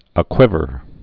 (ə-kwĭvər)